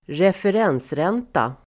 Ladda ner uttalet
referensränta substantiv, Uttal: [²refer'en:sren:ta] Förklaring: Ränta som fastställs för varje kalenderhalvår av Riksbanken.